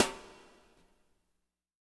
BW BRUSH04-L.wav